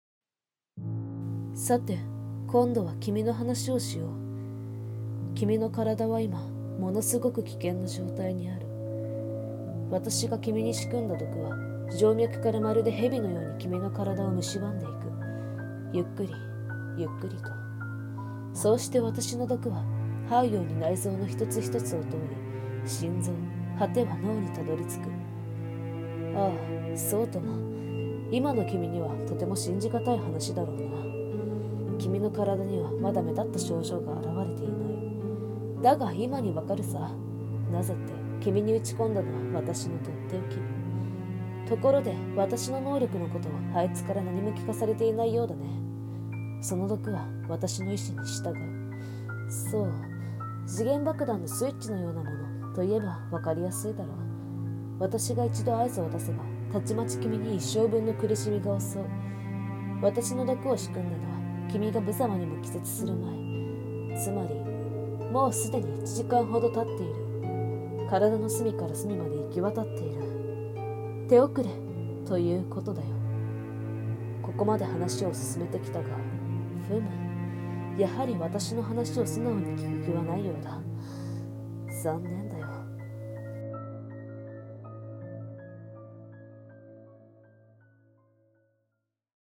声劇 蝕